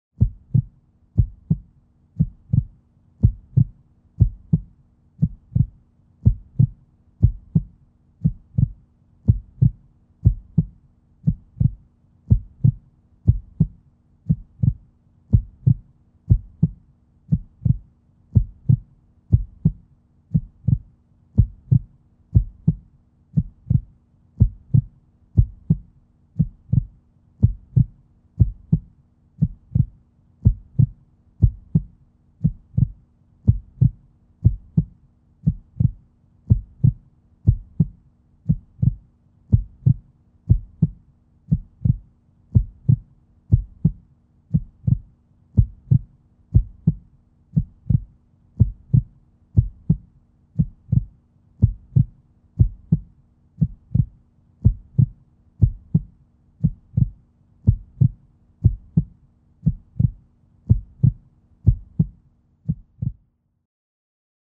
Ритм сердца